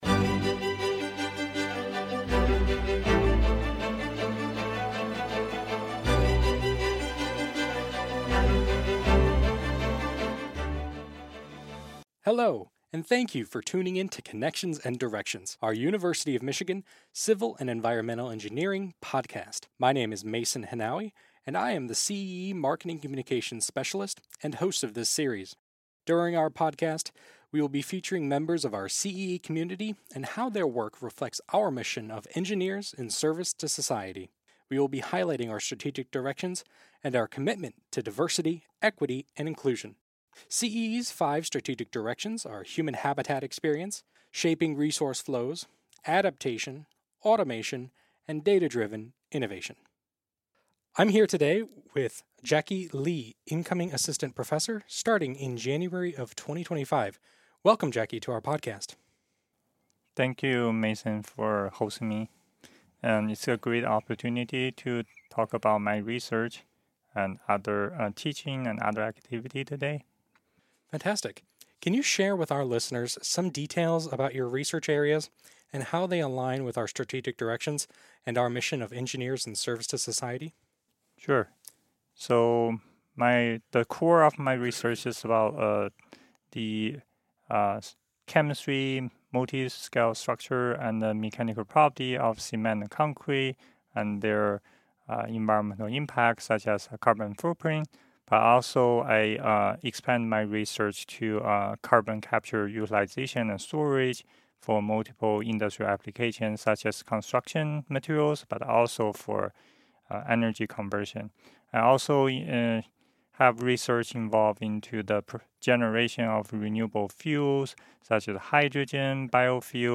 These podcasts highlight our faculty members. During the conversations, they discuss how their work aligns with CEE's Strategic Directions and ways they foster diversity, equity and inclusion in their research and classes.